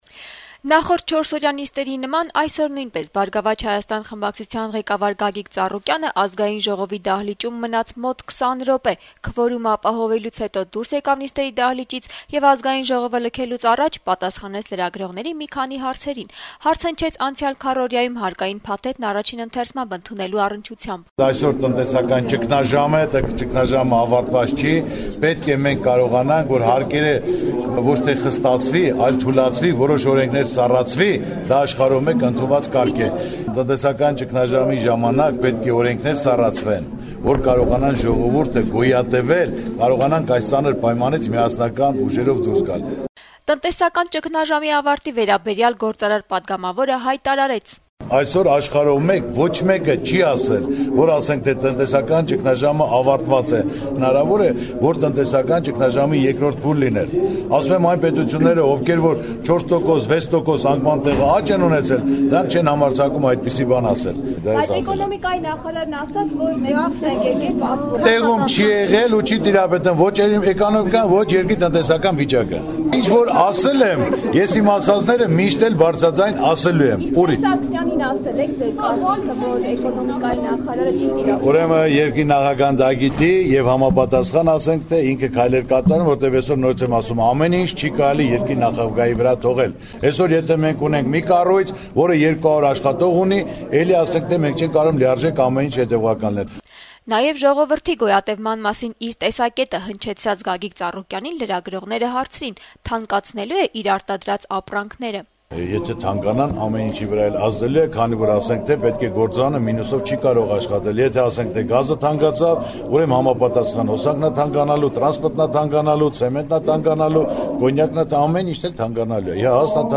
«Բարգավաճ Հայաստան» կուսակցության նախագահ, կուսակցության խորհրդարանական խմբակցության ղեկավար, երկրի ամենախոշոր գործարարներից Գագիկ Ծառուկյանը երկուշաբթի օրը լրագրողների հետ զրույցում կրկին քննադատության ենթարկեց կառավարության կողմից Ազգային ժողով ներկայացված հարկային փոփոխությունների փաթեթը, որը, հիշեցնենք, խորհրդարանը առաջին ընթերցմամբ ընդունել է անցած քառօրյայի ընթացքում: